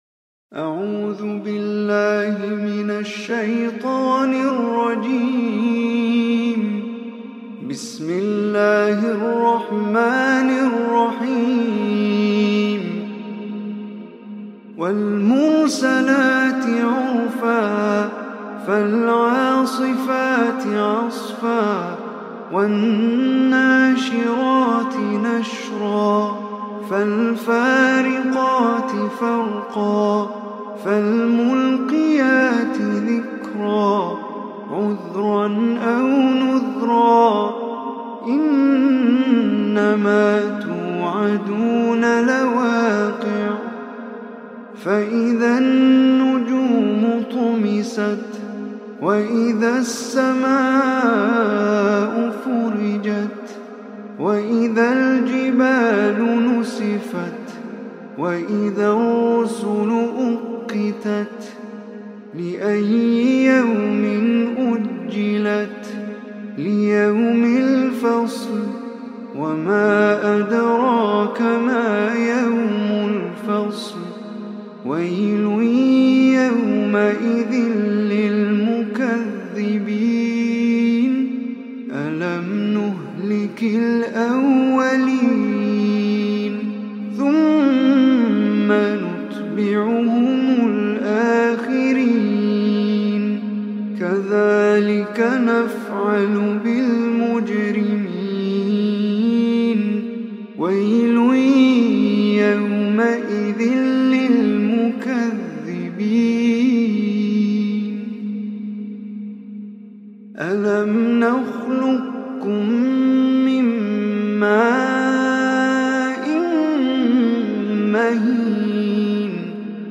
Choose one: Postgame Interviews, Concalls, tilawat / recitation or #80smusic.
tilawat / recitation